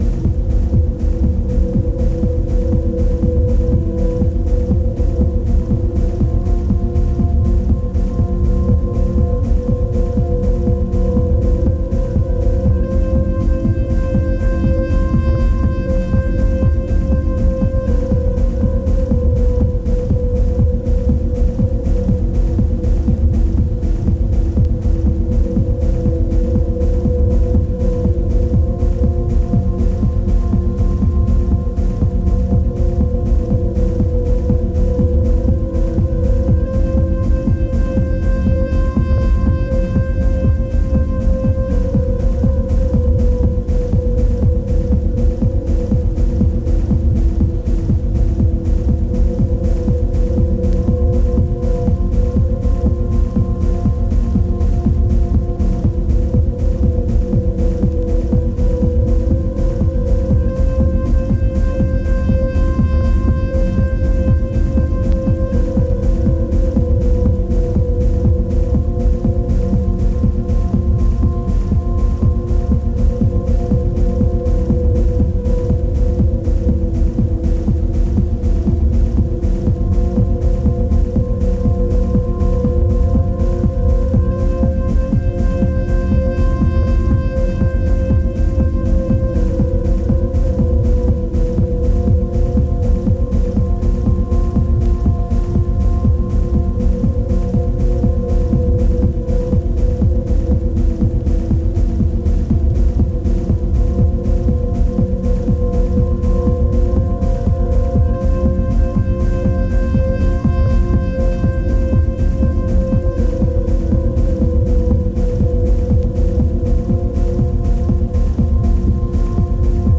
骨組みだけで構成されたミニマルとはまた違ったドローニッシュなサウンド。
拡散、雲散するシンフォニックなレイヤーがじんわりと入ってきてじんわりと抜けていきます。